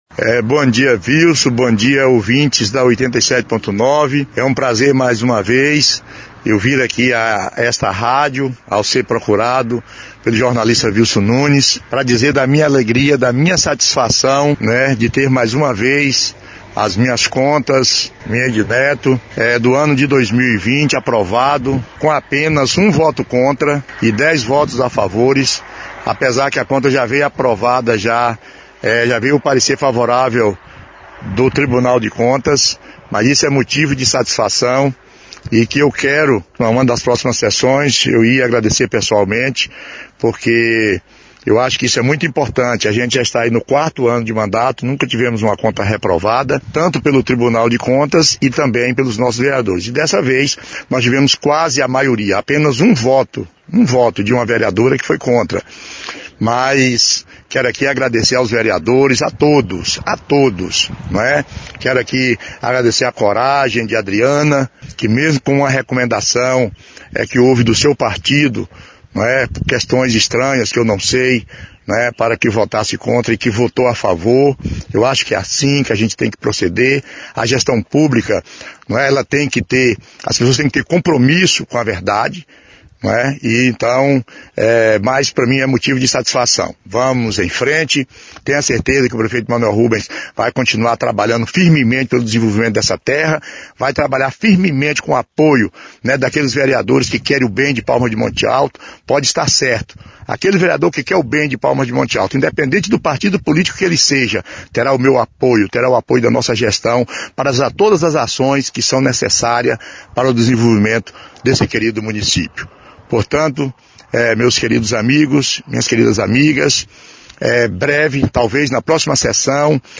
Manoel-Rubens-comenta-sobre-decisão-site.mp3